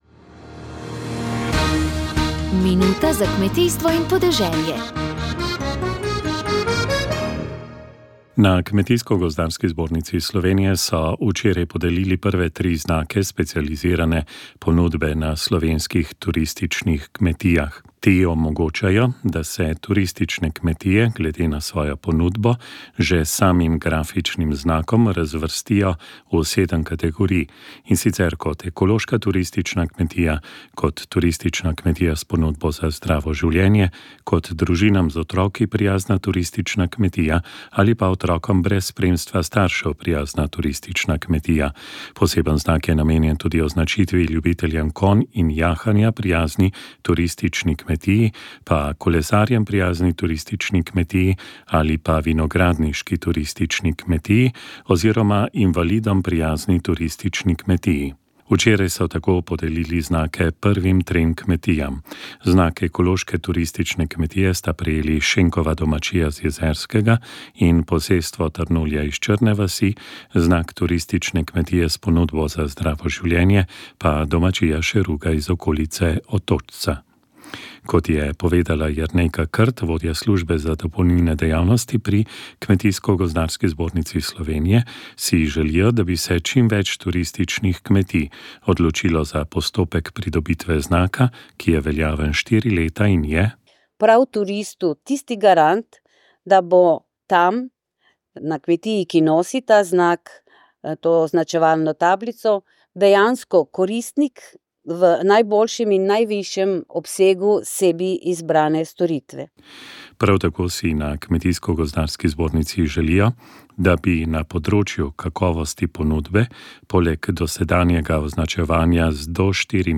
Sv. maša iz cerkve sv. Marka na Markovcu v Kopru 21. 1.
Sveto mašo je ob ljudskem petju